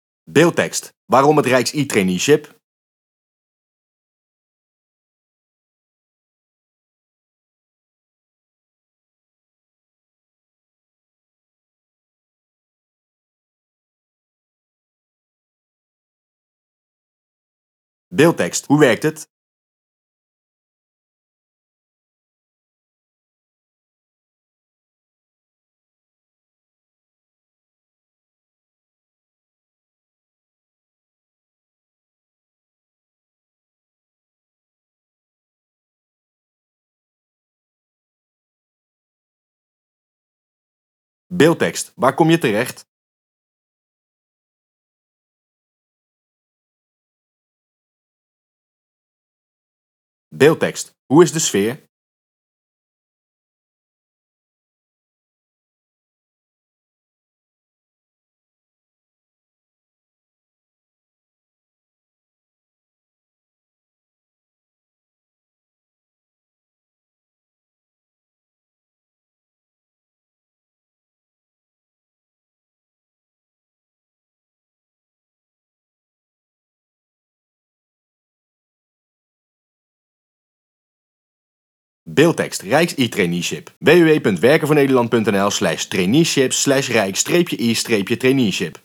Onze trainees aan het woord